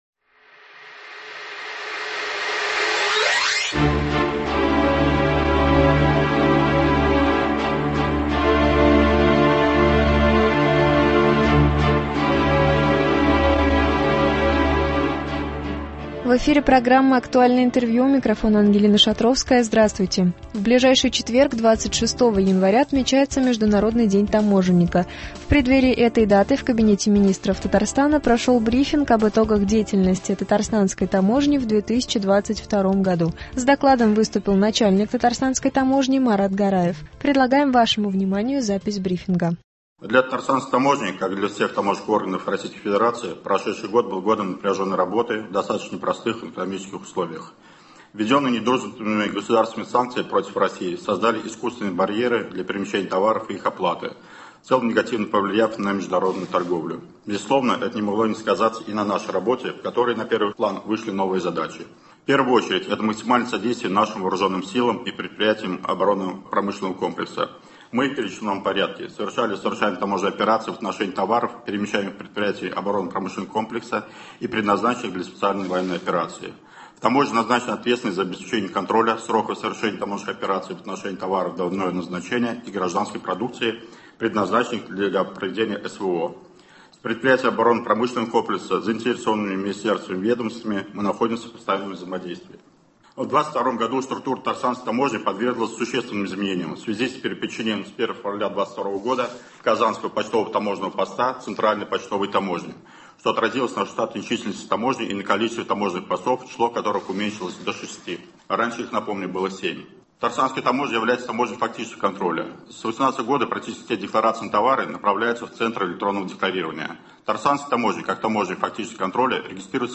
Актуальное интервью (25.01.23)
В преддверии этой даты в Кабинете Министров РТ прошел брифинг об итогах деятельности Татарстанской таможни в 2022 году. С докладом выступил начальник Татарстанской таможни Марат Гараев.